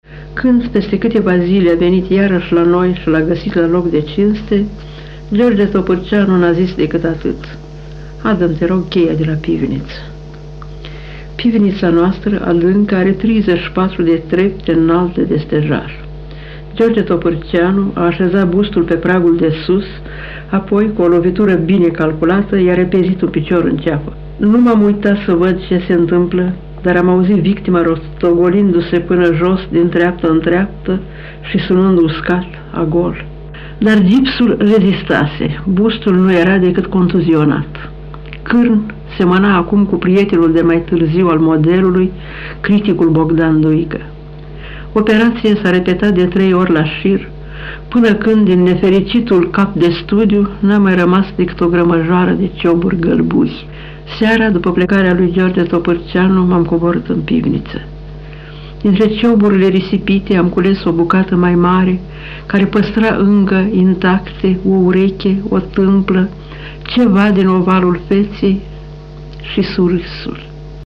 + Audio (MP3) Otilia Cazimir povestește despre George Topîrceanu
Otilia-Cazimir-povesteste.mp3